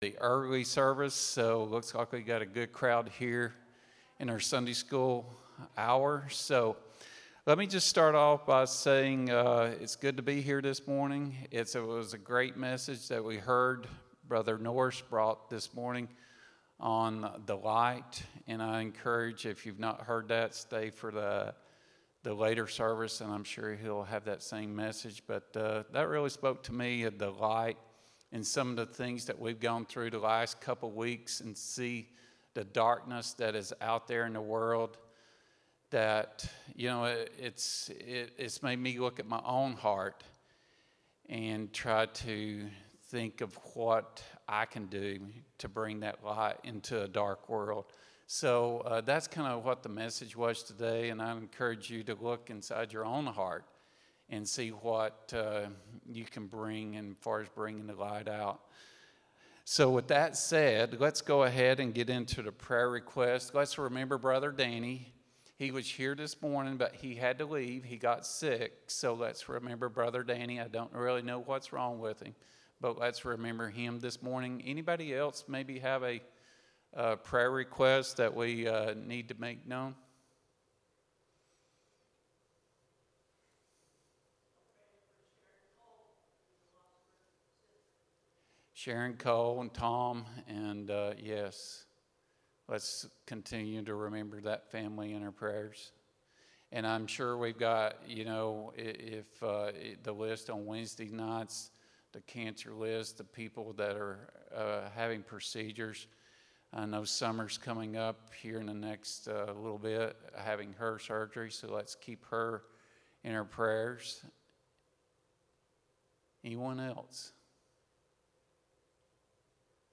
09-28-25 Sunday School | Buffalo Ridge Baptist Church